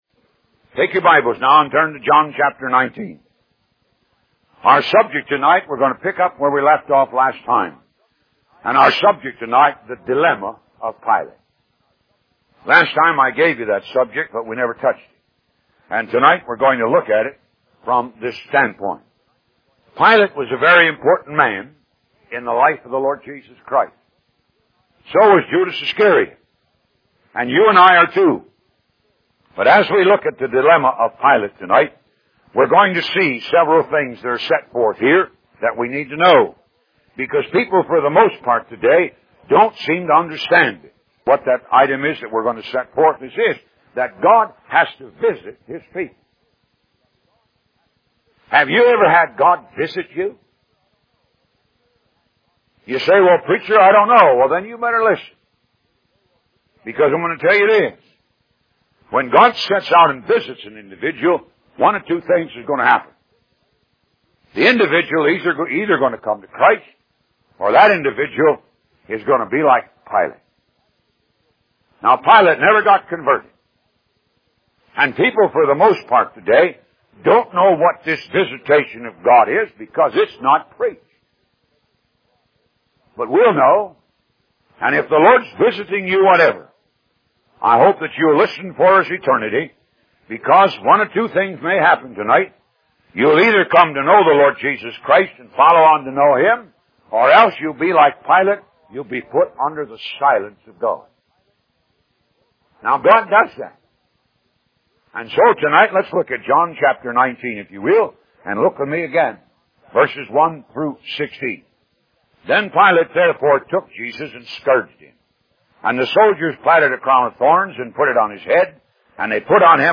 Upon his passing, the Ministry has continued the radio broadcast on some radio stations and through various social media sites.